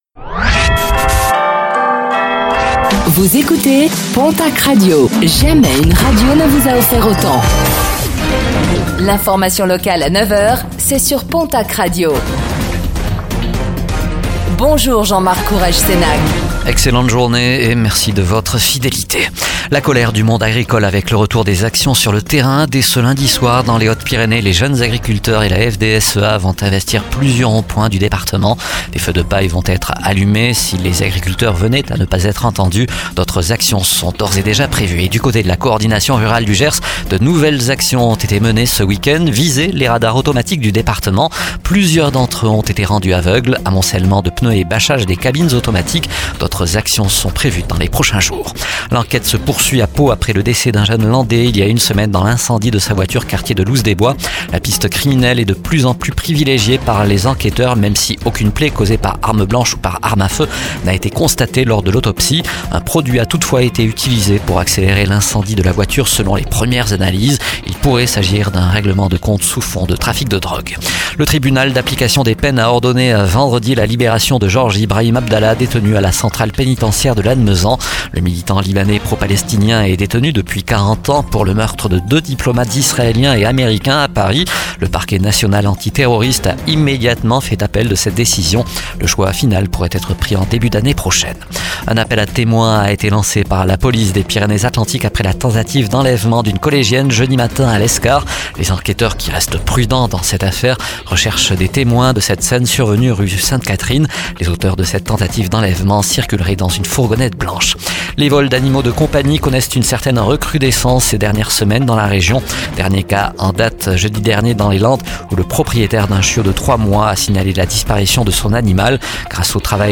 Réécoutez le flash d'information locale de ce lundi 18 novembre 2024